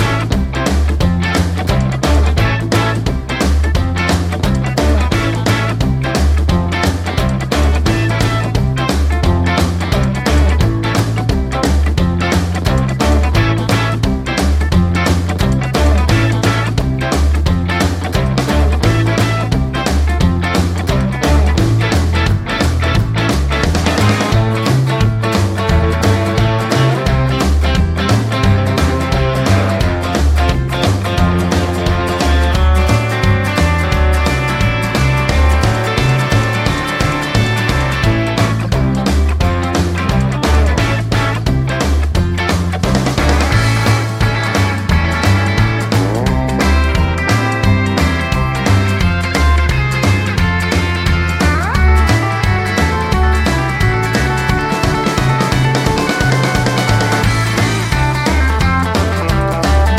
no Backing Vocals Jazz / Swing 2:46 Buy £1.50